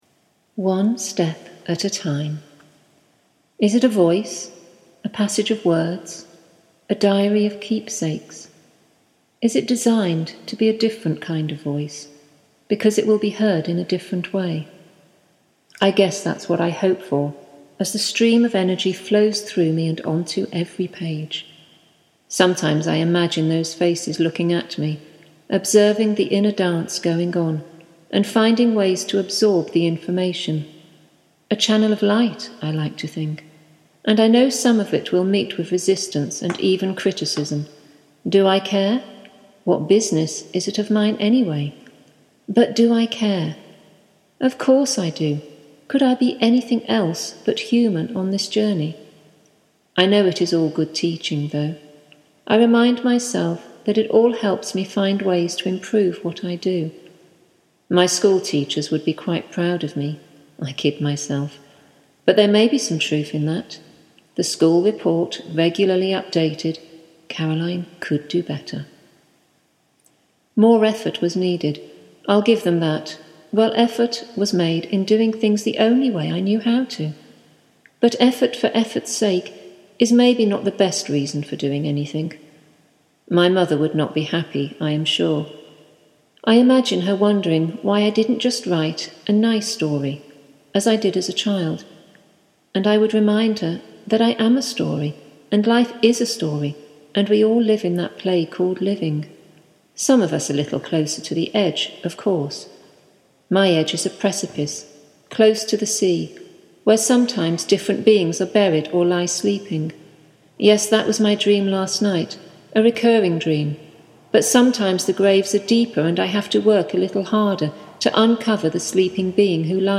one-step-at-a-time-poem.mp3